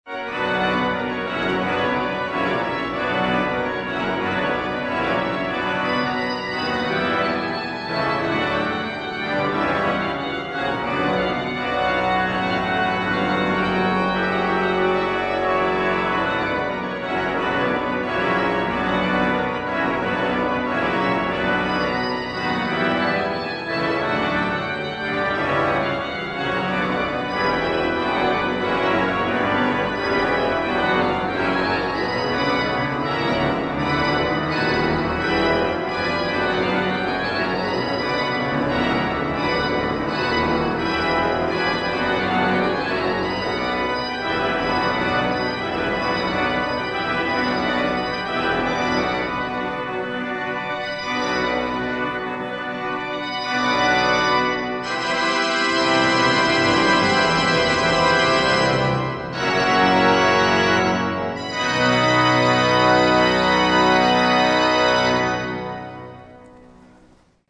Concert sur l'orgue Callinet de l'église Notre-Dame du Marthuret à Riom
Les extraits montrent quelques échantillons des sonorités particulières de l'orgue.